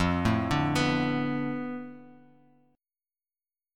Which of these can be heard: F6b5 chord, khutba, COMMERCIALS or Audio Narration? F6b5 chord